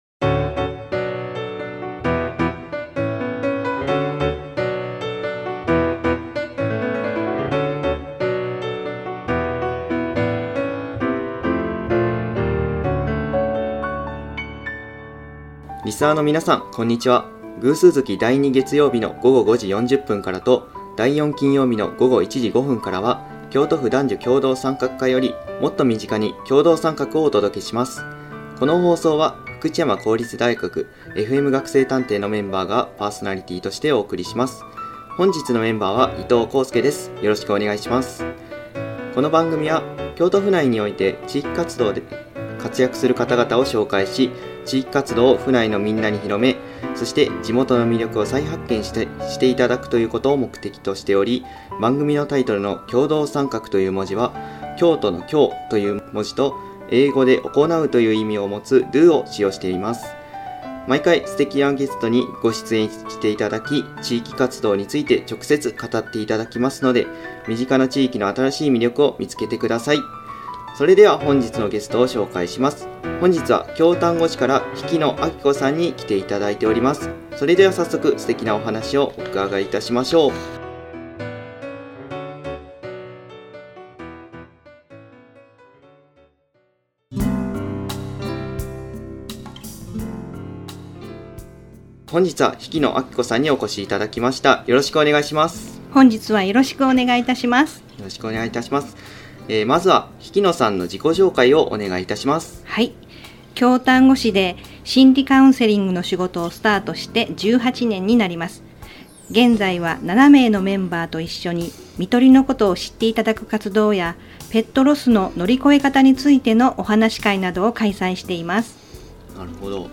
ご本人に語っていただきながら紹介しています！
＜番組進行＞福知山公立大学fm学生探偵